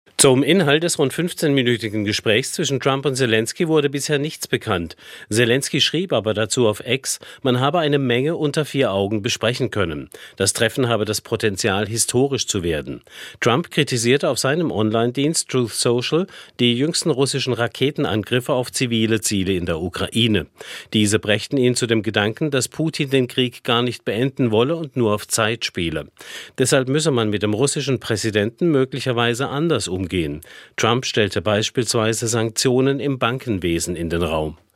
Nachrichten Jetzt droht Trump Russland mit Sanktionen